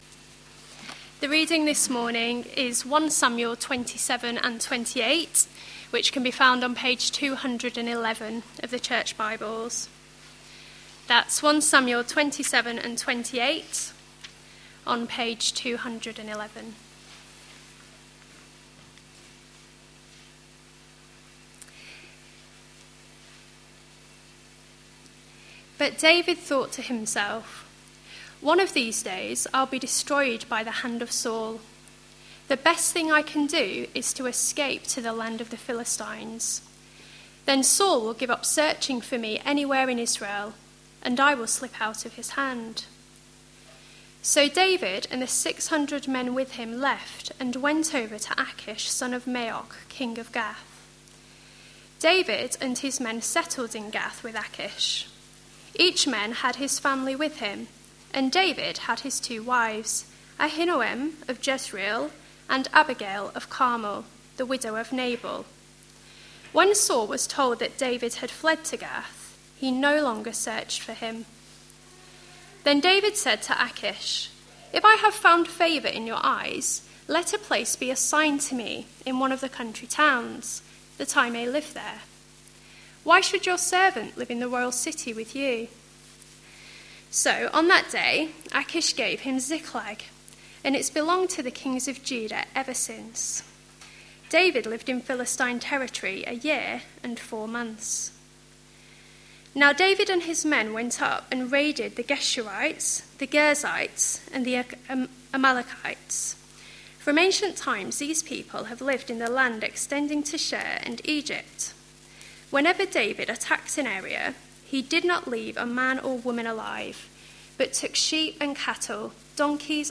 A sermon preached on 11th August, 2013, as part of our God's King? series.